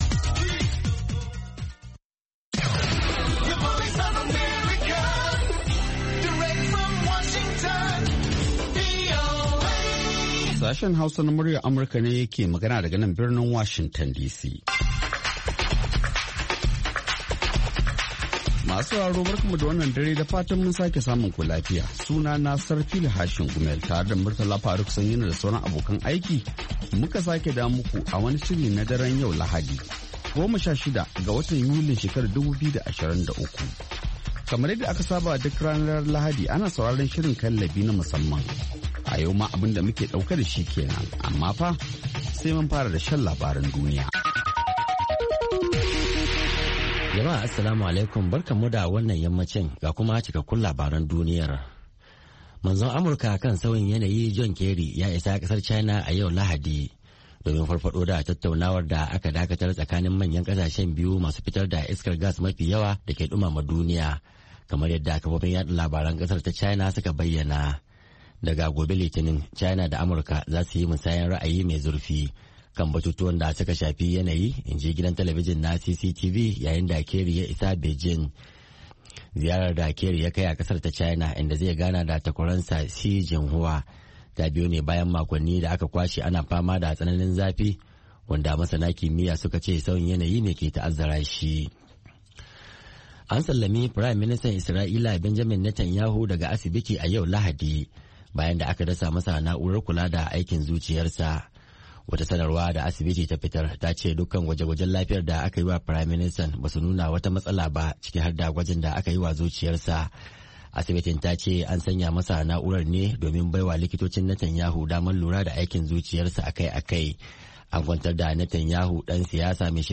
Kallabi wani sabon shiri ne kacokan kan mata, daga mata, a bakin mata, wanda Sashen Hausa na Muryar Amurka ya kirkiro don maida hankali ga baki daya akan harakokin mata, musamman a kasashenmu na Afrika. Shirin na duba rawar mata da kalubalensu ne a fannoni daban-daban na rayuwa.